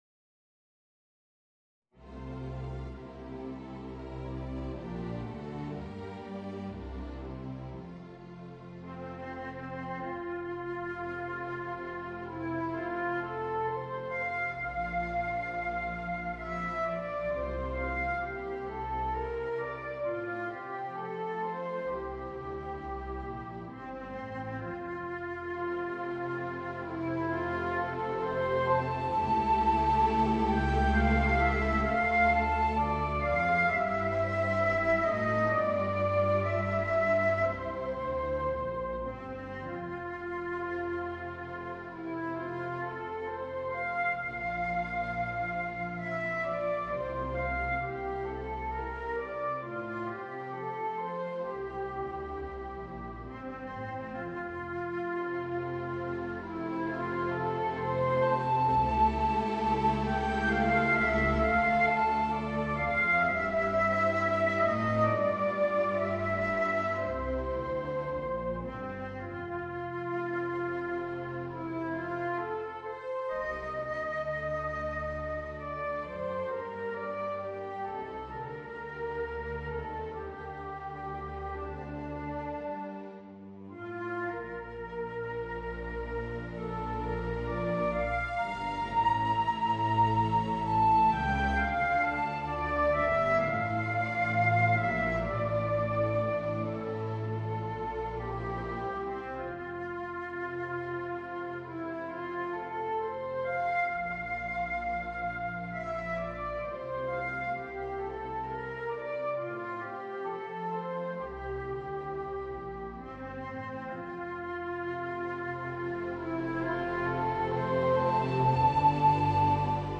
Voicing: Viola and String Orchestra